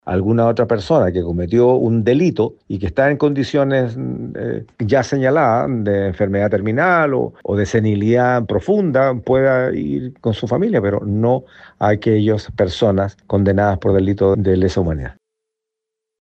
El senador de la Democracia Cristiana, Iván Flores, señaló que es comprensible otorgar un cumplimiento alternativo de condena a personas con enfermedades terminales, demencia o un grave deterioro físico.